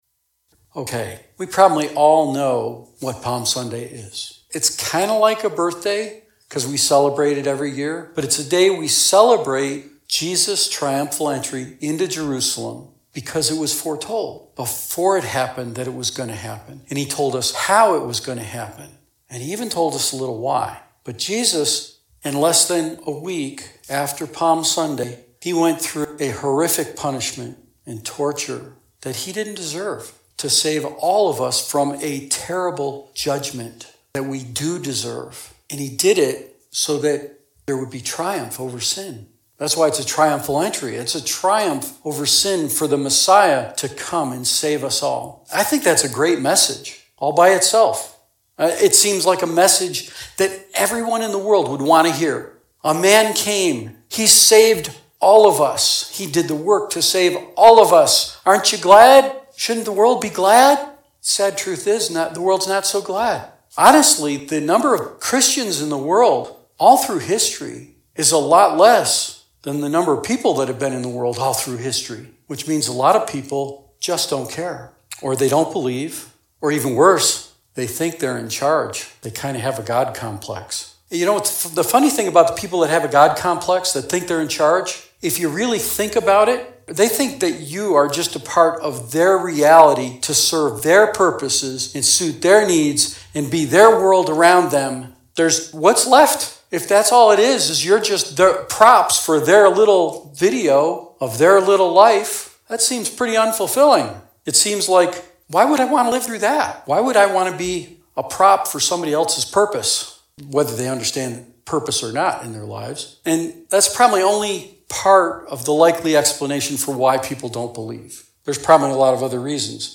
The coming of the Messiah was prophesied long before the famous Triumphal Entry of Jesus Christ, but scholars have shown that God’s plan for Jesus entry into Jerusalem to announce the coming of His Kingdom proves that Jesus arrived at the exact point in time God’s plans predicted His coming. For this Palm Sunday message, we look at some of the prophecies that predicted His coming so that we can rest assured that God has everything completely under control.